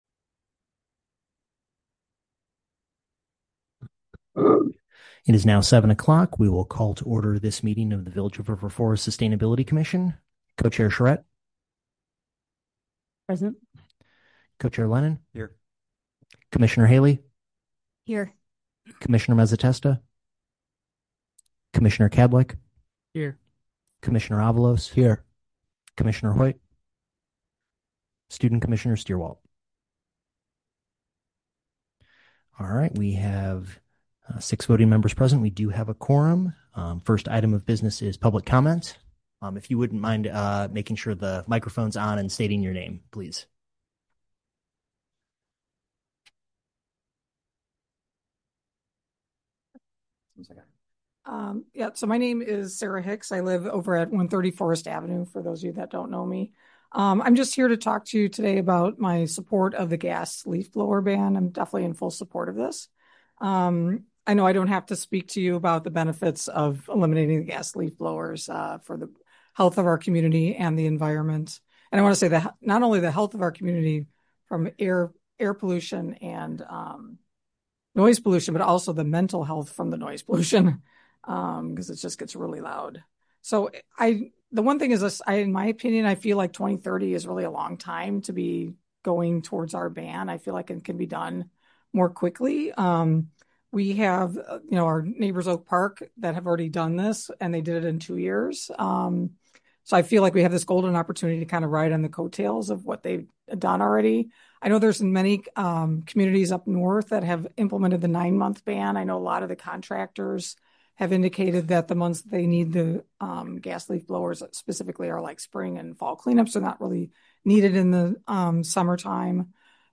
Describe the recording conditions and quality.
Village Hall - 400 Park Avenue - River Forest - IL - COMMUNITY ROOM